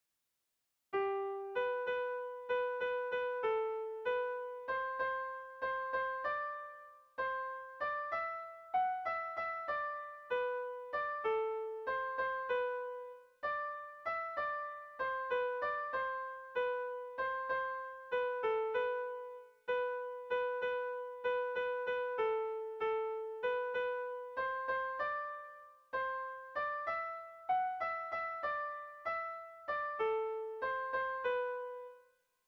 Kontakizunezkoa
Hamarreko txikia (hg) / Bost puntuko txikia (ip)
ABDEB